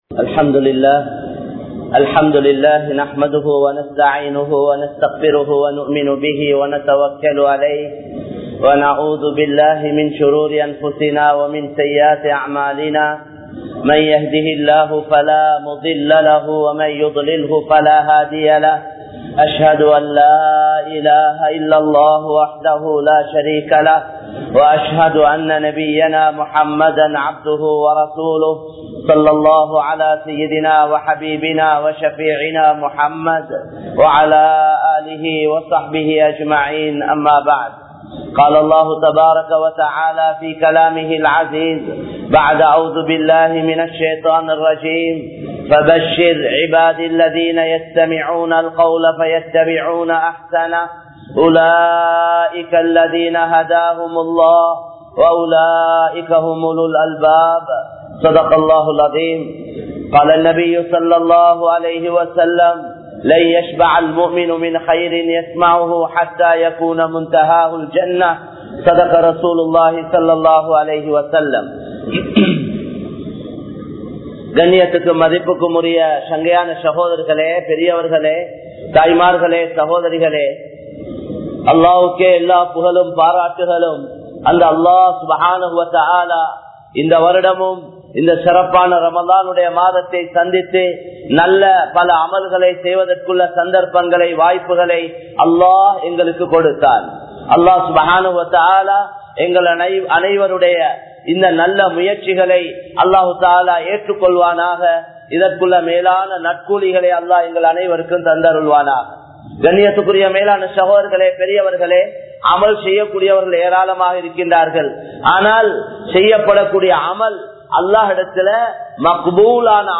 Nilamaihalai Maattrufavan Allah (நிலமைகளை மாற்றுபவன் அல்லாஹ்) | Audio Bayans | All Ceylon Muslim Youth Community | Addalaichenai
Colombo 03, Kollupitty Jumua Masjith